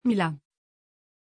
Aussprache von Milàn
pronunciation-milàn-tr.mp3